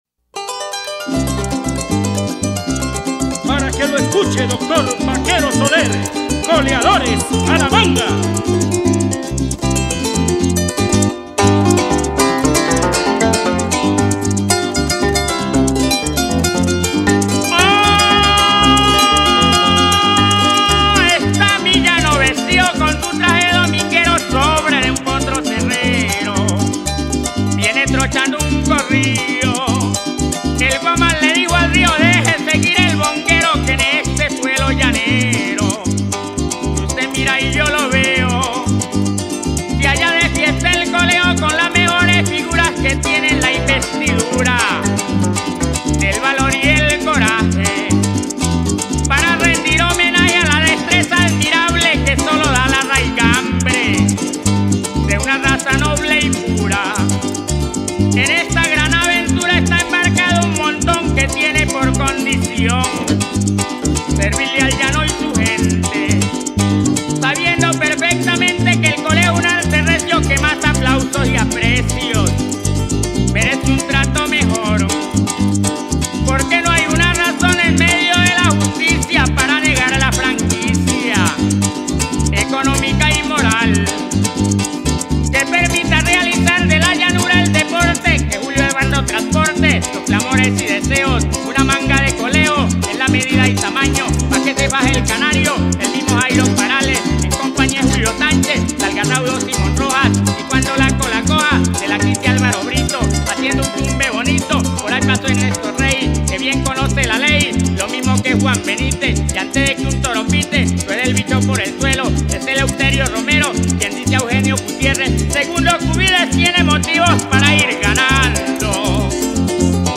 Ritmo: Seis por derecho.